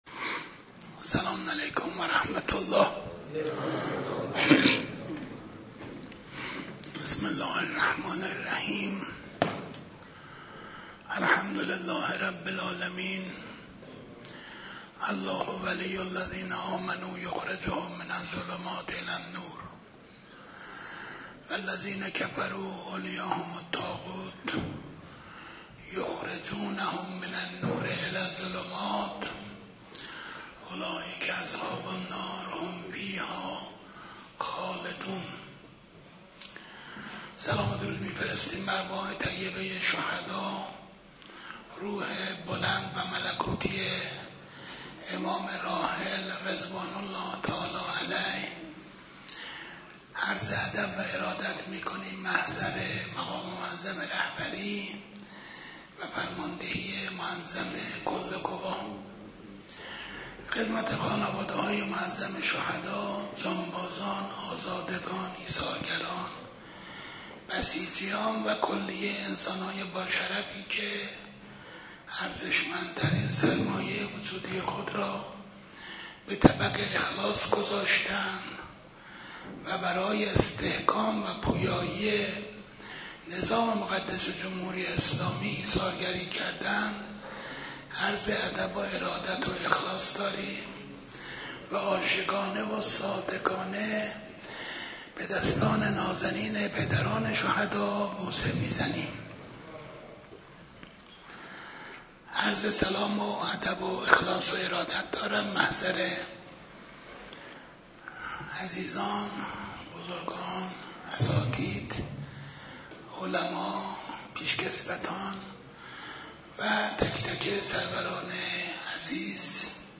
🎙سخنرانی سردار نوعی اقدم در جلسه ماهانه - رجب 1445